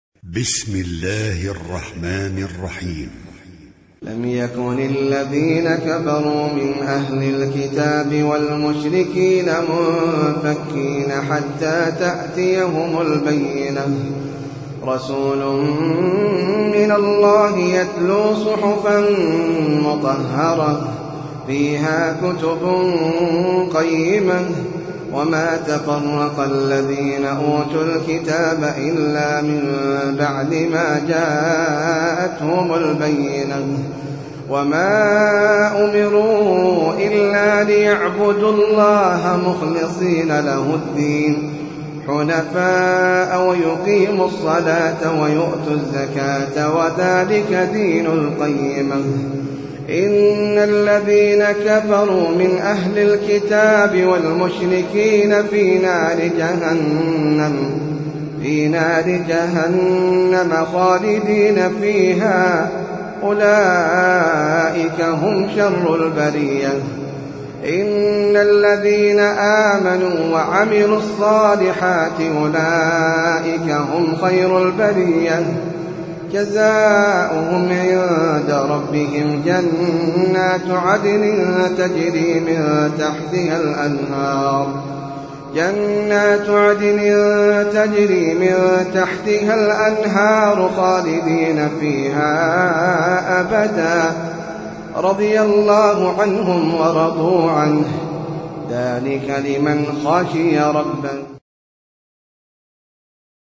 سورة البينة - المصحف المرتل (برواية حفص عن عاصم)
جودة عالية